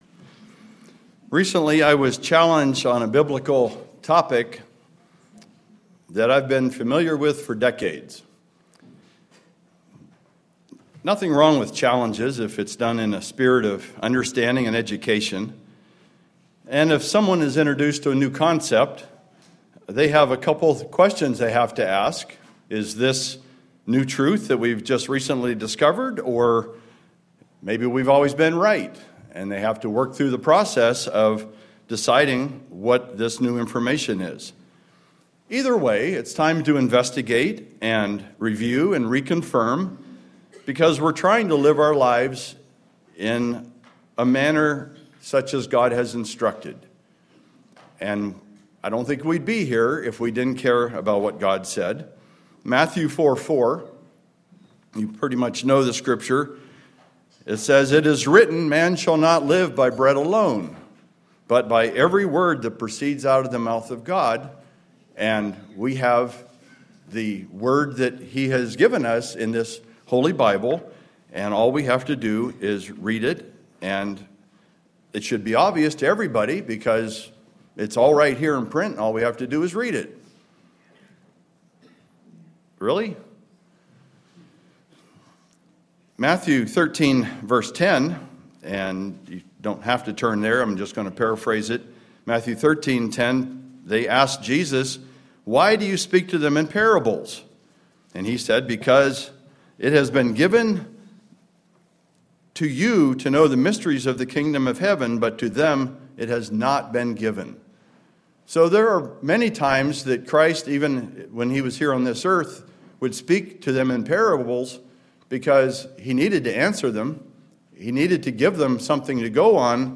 Sermons
Given in Kennewick, WA Chewelah, WA Spokane, WA